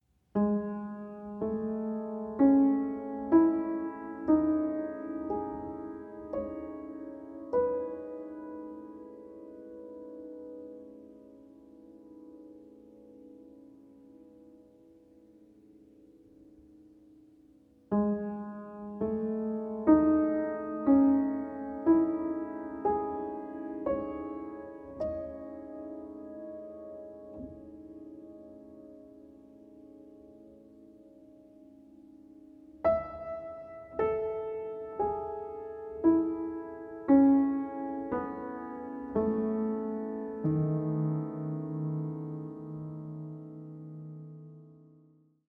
重ねた日々をひとつひとつスケッチしたのような短い曲たち。余韻が深く、沈み込む。